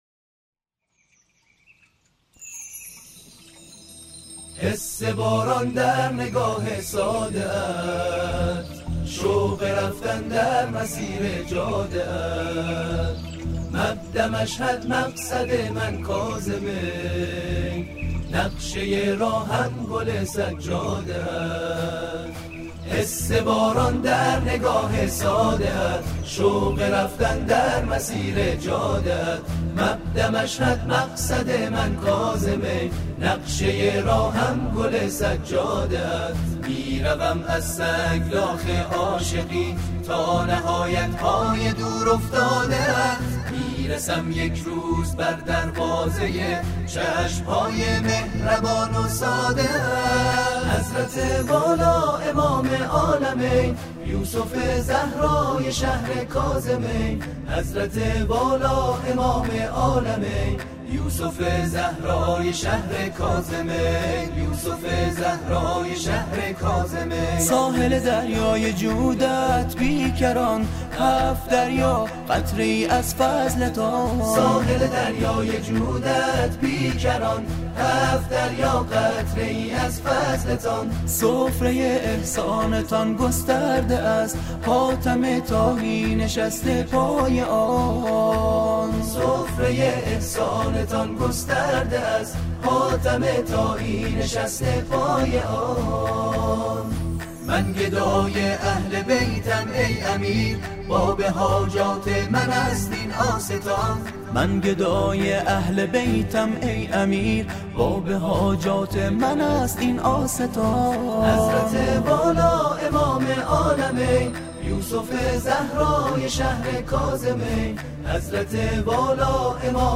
تواشیح میلاد امام جواد